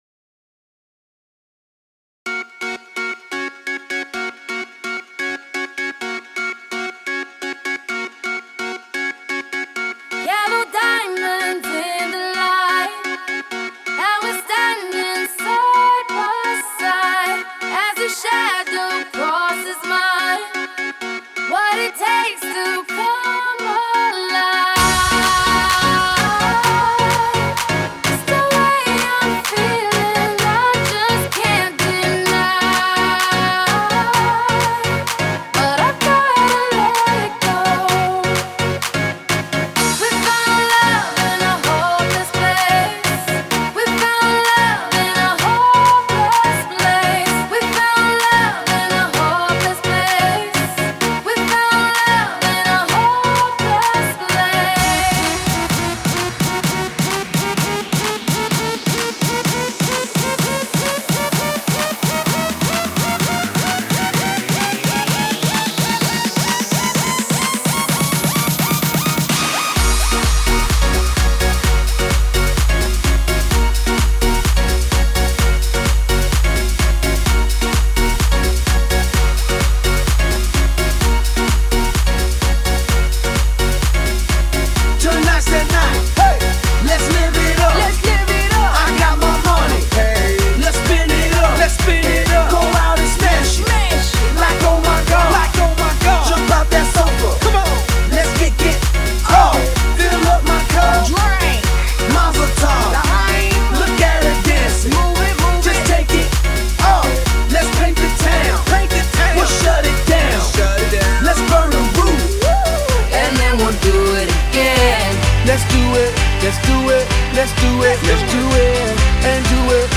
These are examples to show skill, voice, and appearance of some of our Philadelphia wedding djs.
Live Wedding Mix